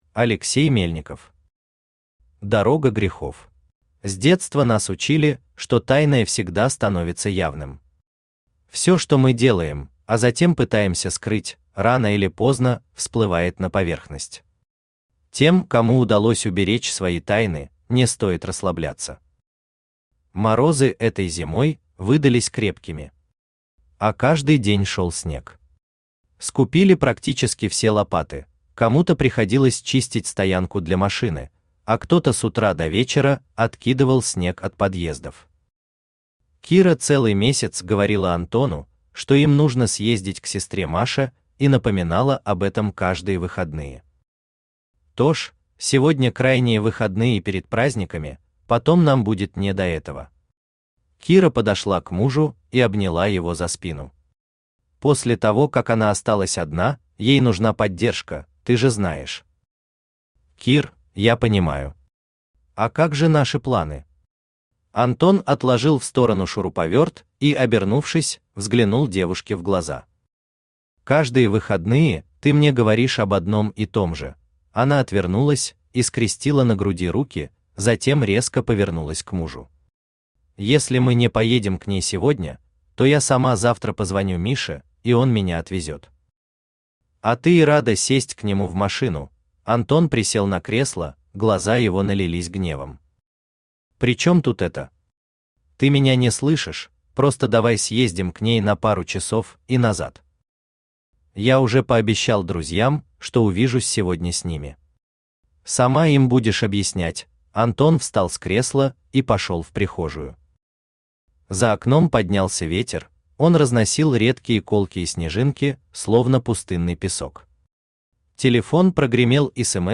Аудиокнига Дорога грехов | Библиотека аудиокниг
Aудиокнига Дорога грехов Автор Алексей Романович Мельников Читает аудиокнигу Авточтец ЛитРес.